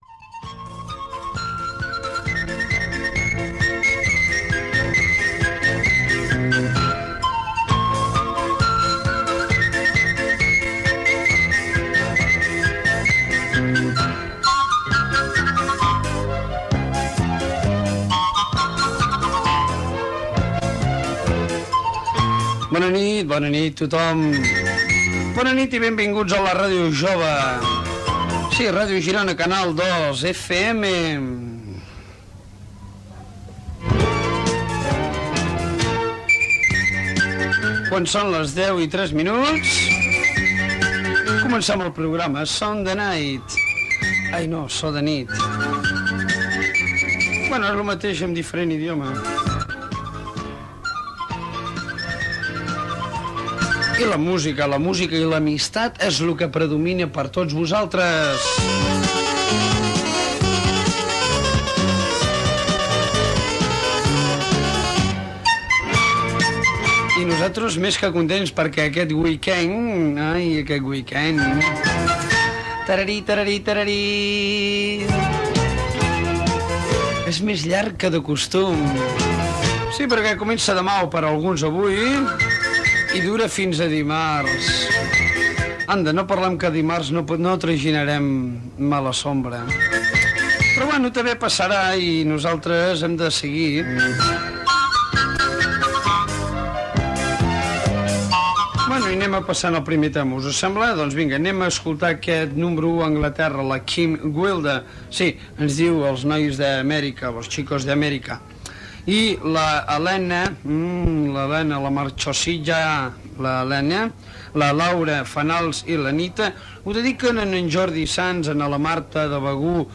Sintonia, identificació de l'emissora i del programa, hora, presentació, comentari sobre el cap de setmana llarg, tema musical dedicat
Musical
FM